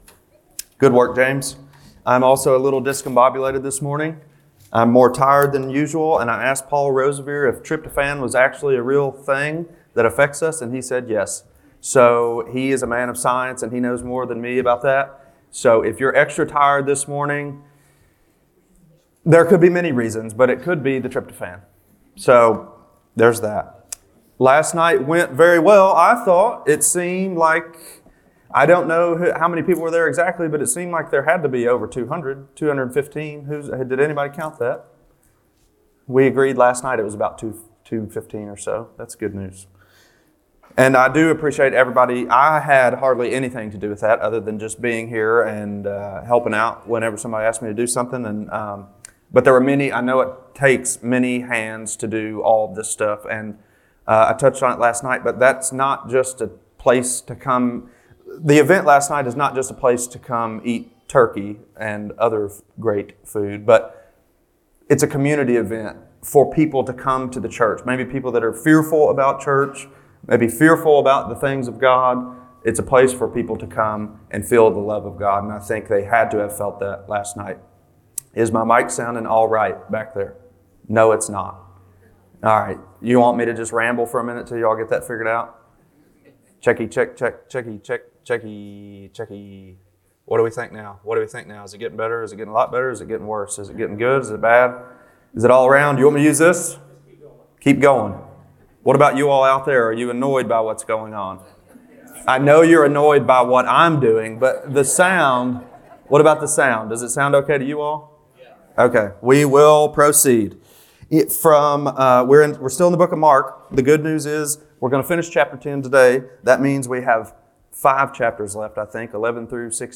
Sunday Morning Services | Belleview Baptist Church